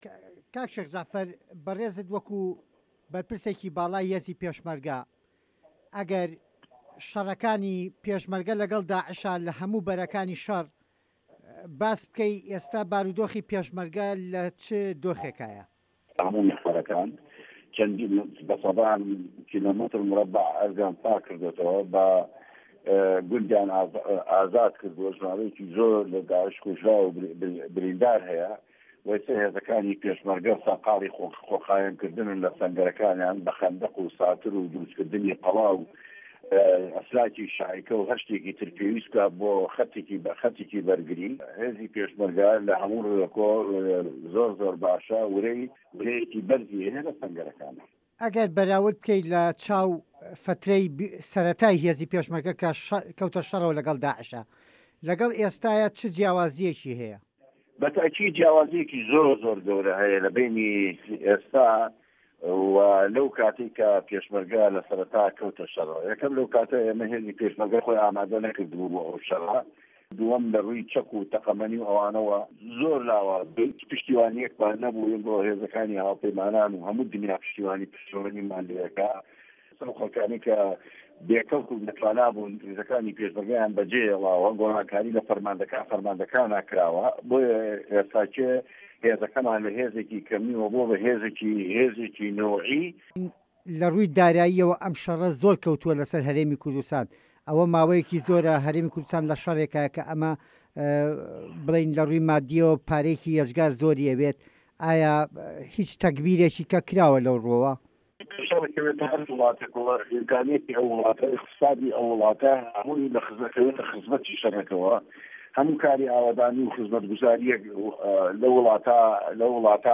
وتووێژ لەگەڵ شێخ جەعفەر مستەفا